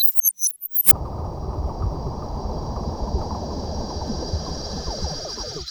Vpar Electrical.wav